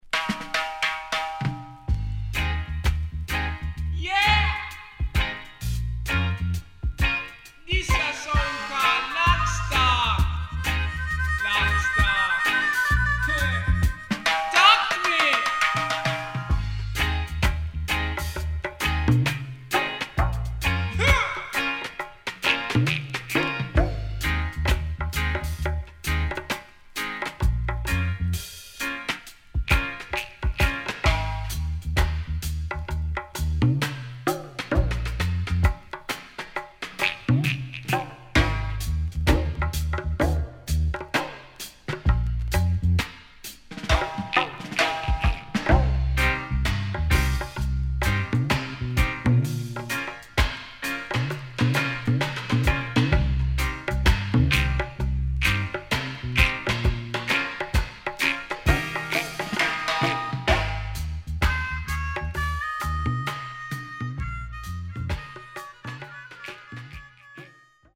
SIDE A:少しノイズ入りますが良好です。